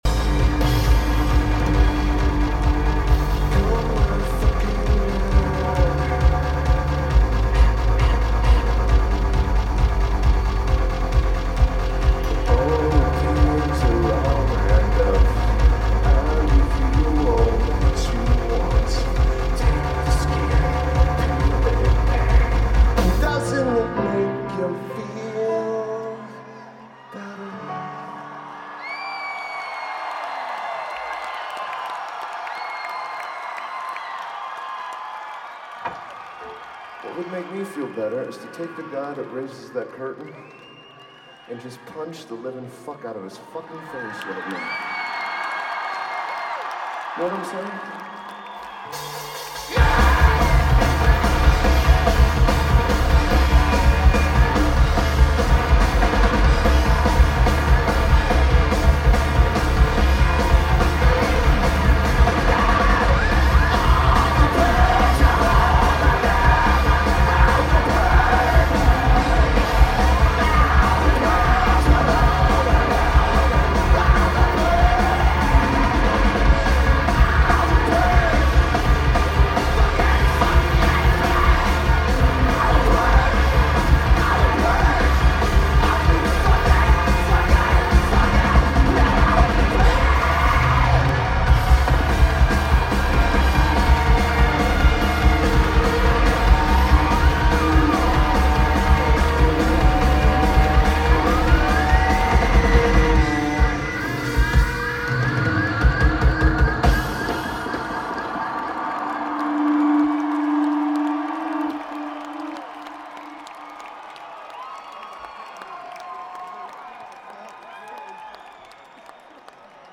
Wachovia Arena
Guitar
Keyboards/Bass/Backing Vocals
Drums
Bass
Lineage: Audio - AUD (Schoeps MK4s + Nbox + Sony PCM-M1)